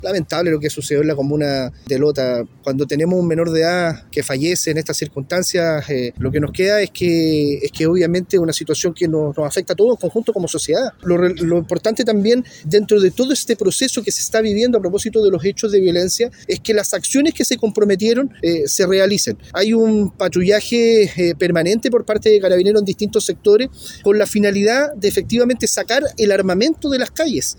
Su trágico deceso fue lamentado por el delegado Presidencial regional de Bío Bío, Eduardo Pacheco, quien consignó que las policías trabajan en reducir la circulación de armas de fuego en el Gran Concepción.
delegado-x-adolescente.mp3